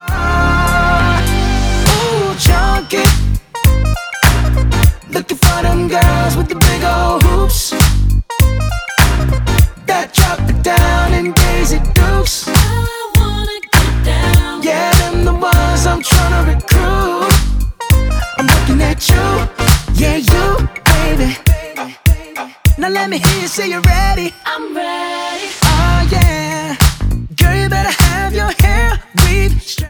• Pop
a funk and electro-funk ballad